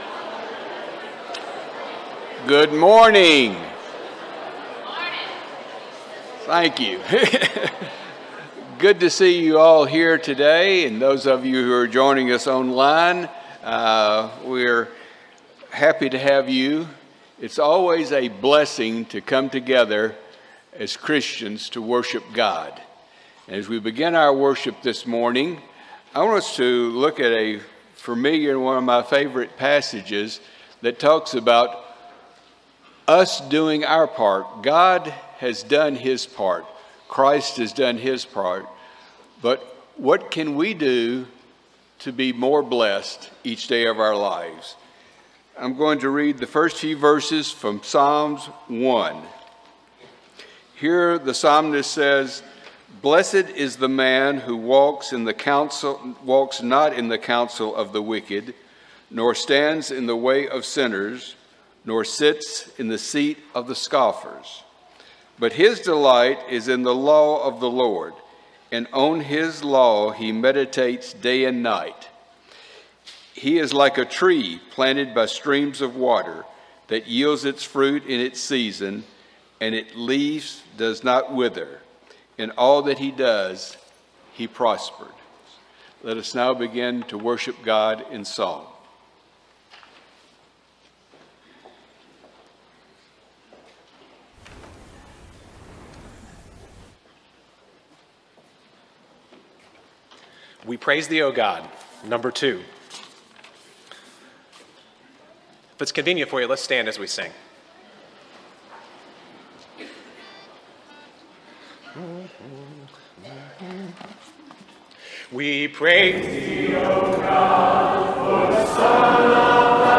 Isaiah 26:4, English Standard Version Series: Sunday AM Service